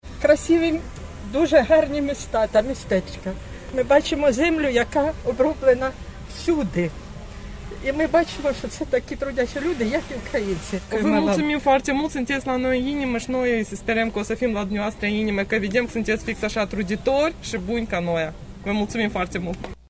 La vernisajul expoziției au fost prezenți și câțiva ucraineni. Emoționați, au mulțumim pentru tot ajutorul pe care l-au primit: